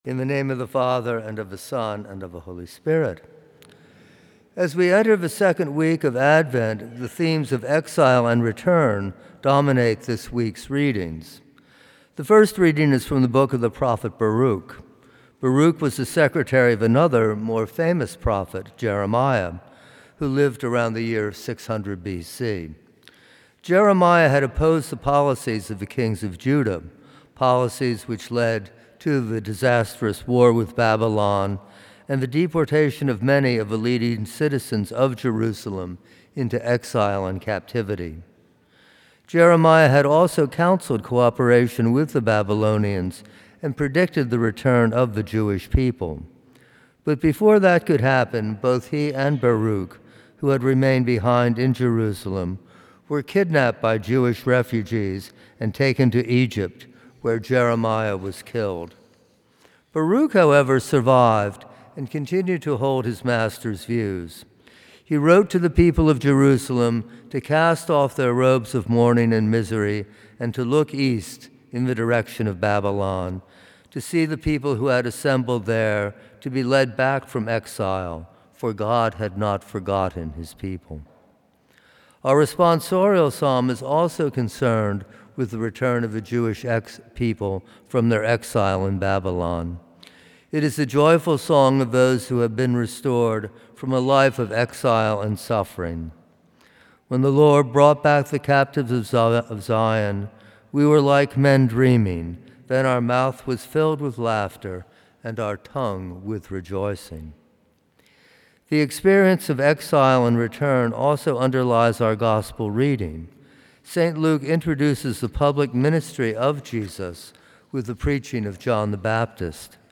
Homily
From Series: "Homilies"